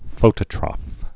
(fōtə-trŏf, -trōf)